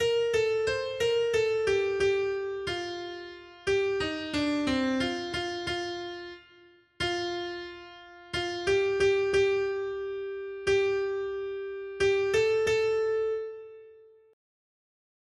Noty Štítky, zpěvníky ol250.pdf responsoriální žalm Žaltář (Olejník) 250 Skrýt akordy R: Ochraň, Pane, duši mou v pokoji u tebe. 1.